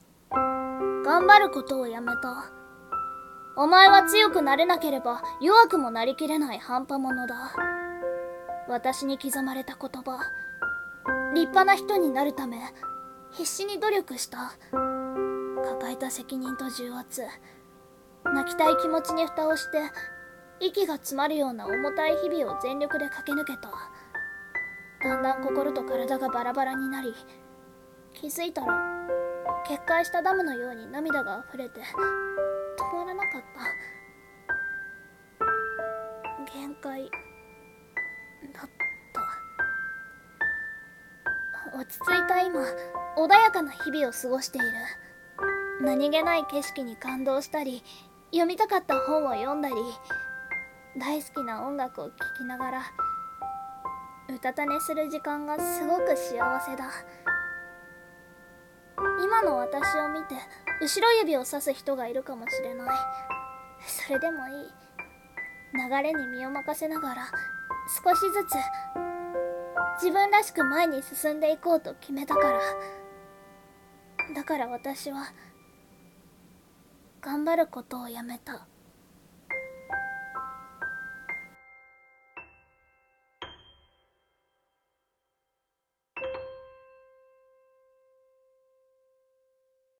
【声劇】頑張ることをやめた。